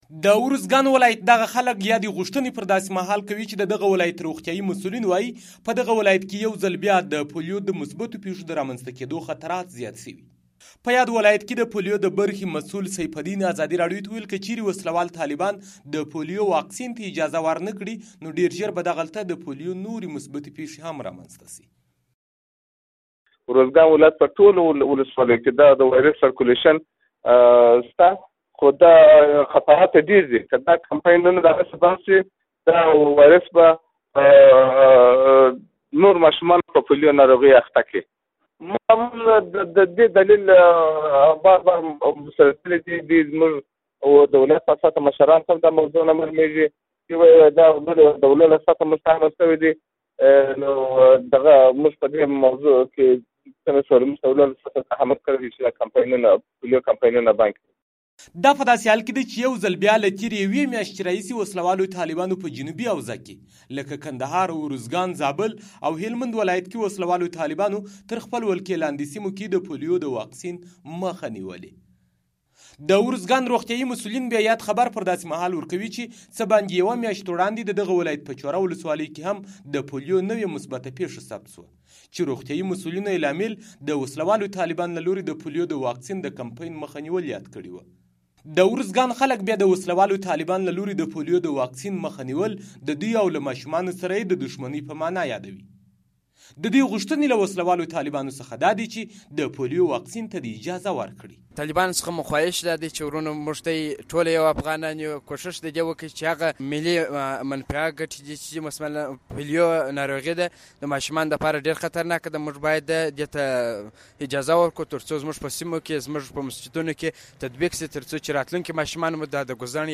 د ارزګان راپور